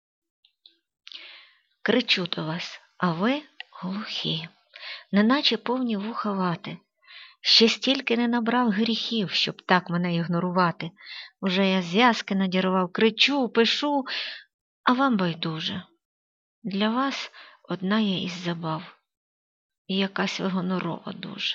Якщо голос твій, то я сподівався трохи міцнішого, строгішого. А він ніжний та добрий 12